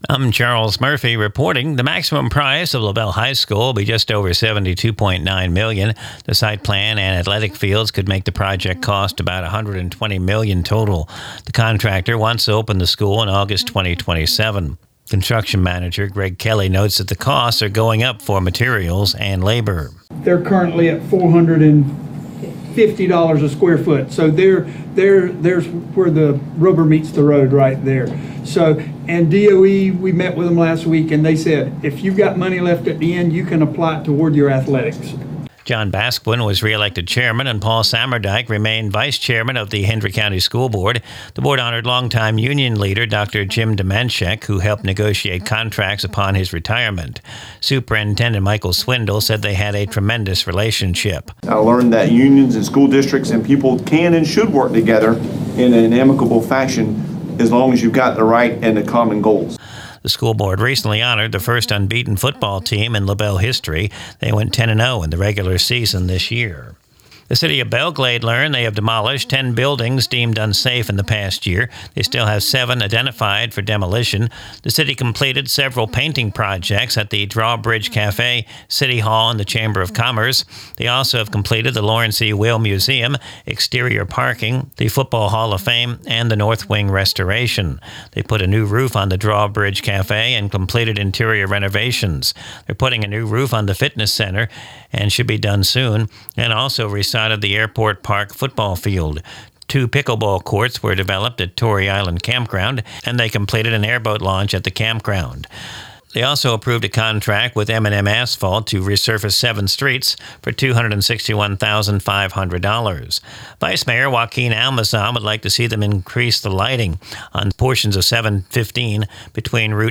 Recorded from the WAFC daily newscast (Glades Media).